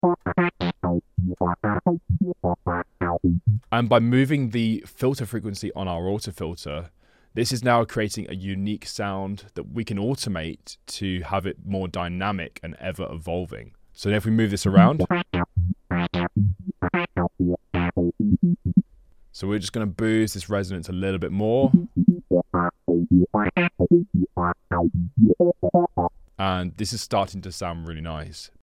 By adjusting the frequency on an Auto Filter, you can craft a unique, resonant sound that evolves over time with automation. It’s a powerful way to add movement and character to your top bass layers, helping them cut through and stand out.